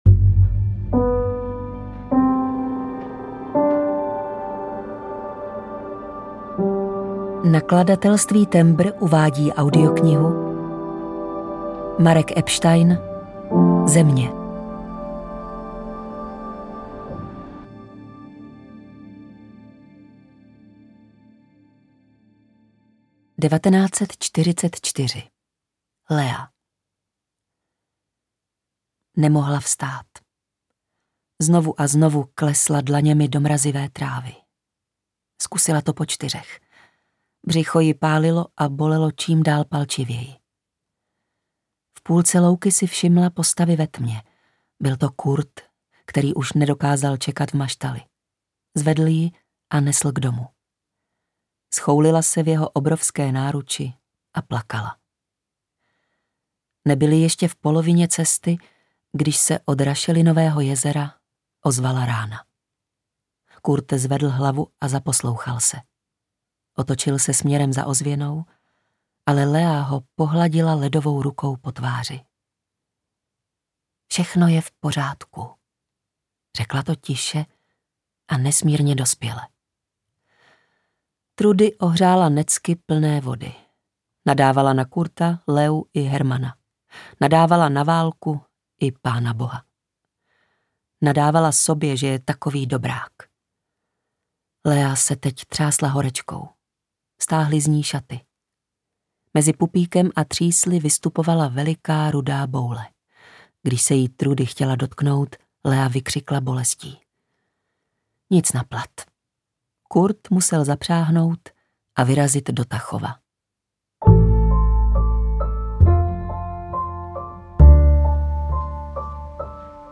ZEMĚ audiokniha
Audiokniha Země, kterou napsal Marek Epstein.
Ukázka z knihy